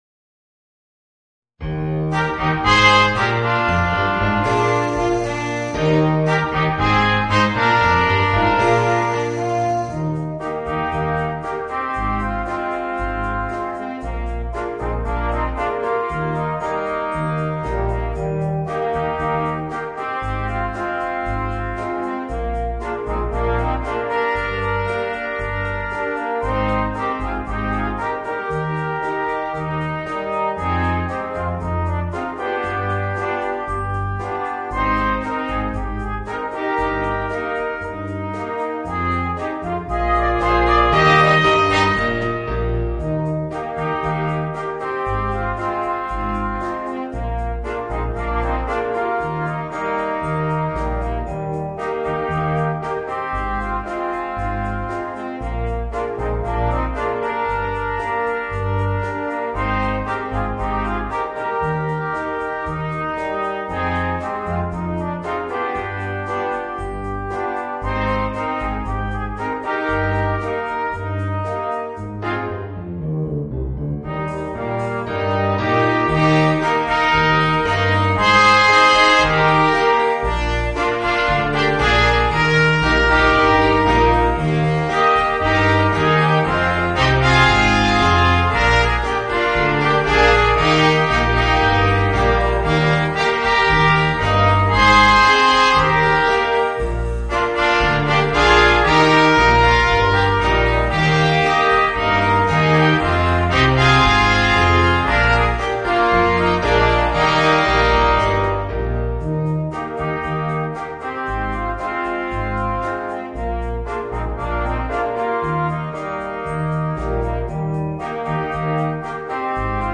Voicing: 2 Trumpets, Horn and Bass Trombone